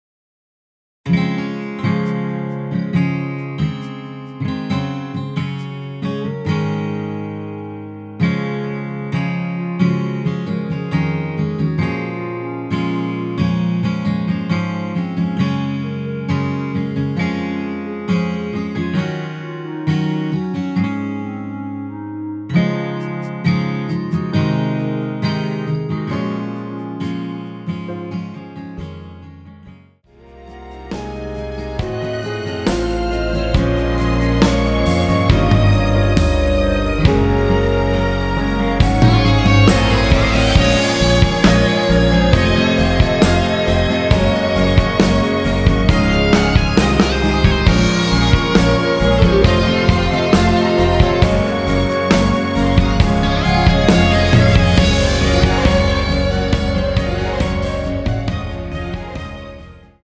원키(1절+후렴) 멜로디 포함된 MR입니다.(미리듣기 확인)
Db
앞부분30초, 뒷부분30초씩 편집해서 올려 드리고 있습니다.